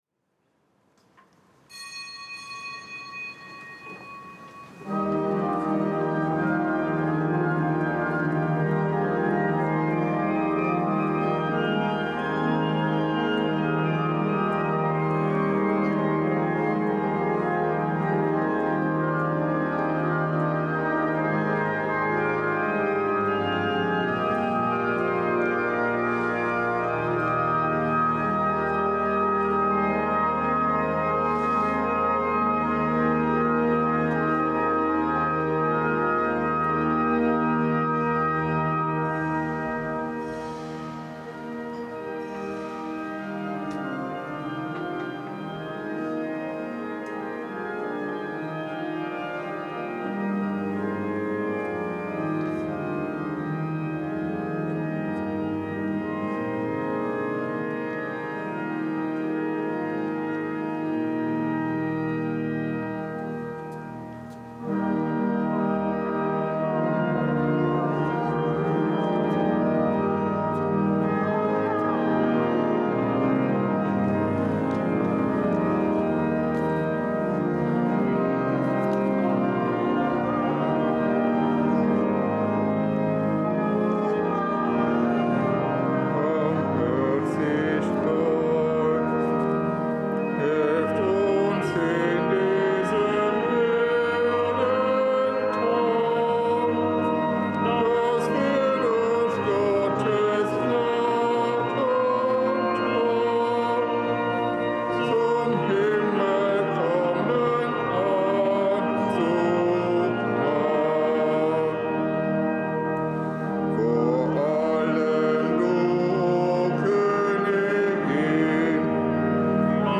Gottesdienst
Kapitelsmesse aus dem Kölner Dom am Samstag der 18. Woche im Jahreskreis.